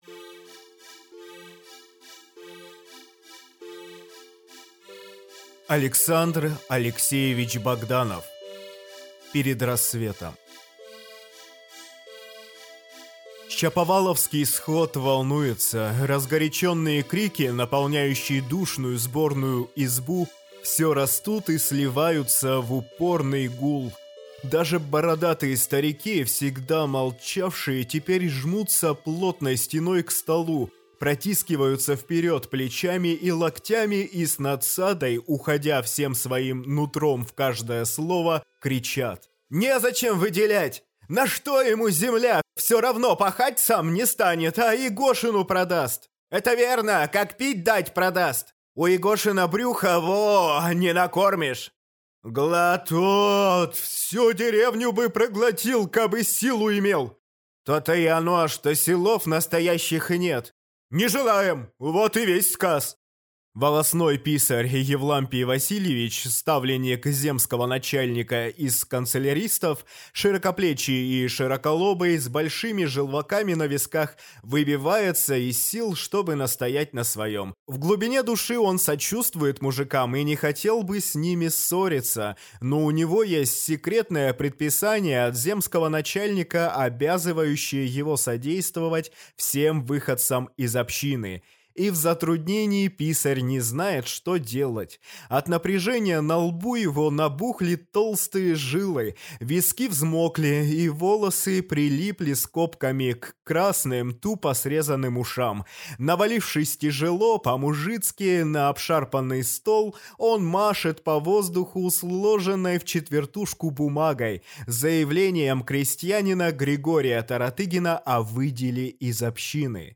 Аудиокнига Перед рассветом | Библиотека аудиокниг